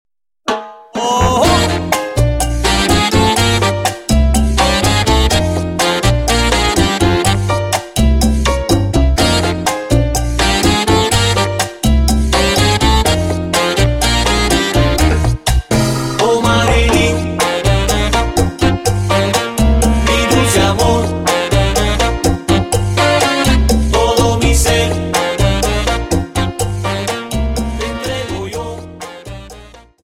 Cha Cha Song